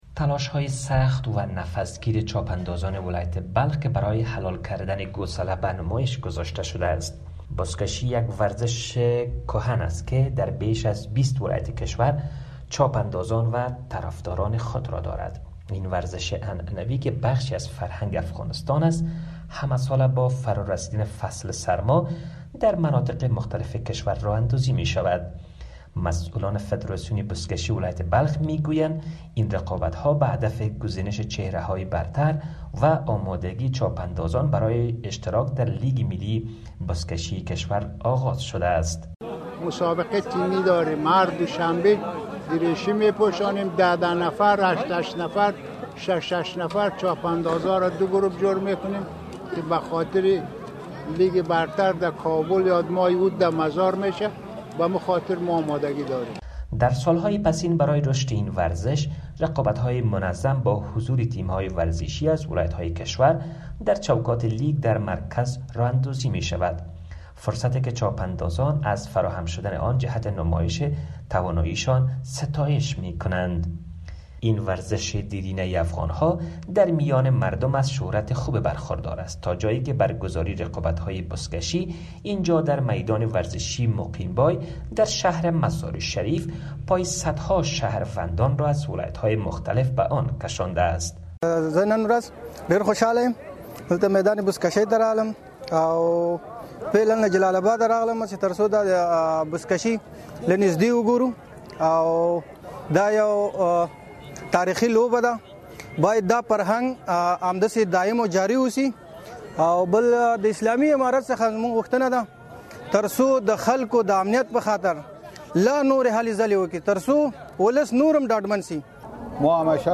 خبر / ورزشی